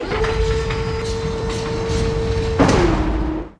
ind_lift1.wav